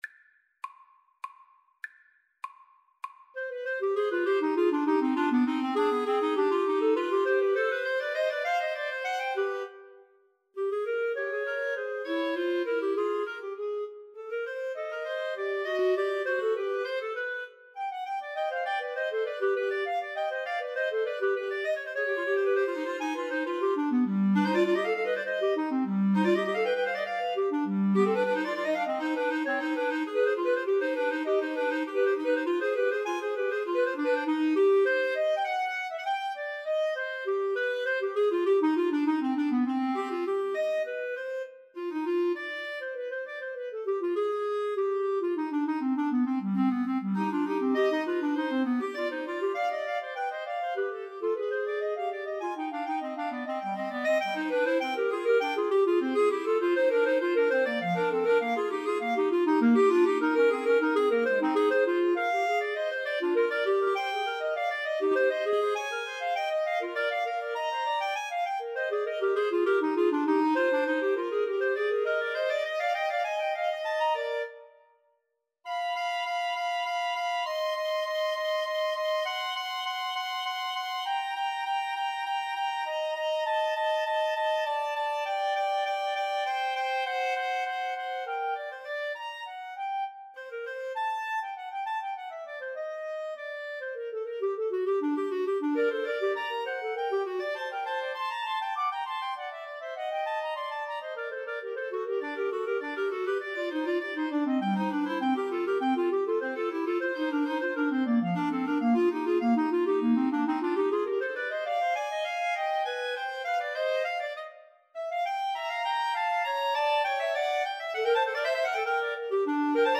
Free Sheet music for Clarinet Trio
Allegro (View more music marked Allegro)
3/4 (View more 3/4 Music)
C minor (Sounding Pitch) D minor (Clarinet in Bb) (View more C minor Music for Clarinet Trio )
Clarinet Trio  (View more Advanced Clarinet Trio Music)
Classical (View more Classical Clarinet Trio Music)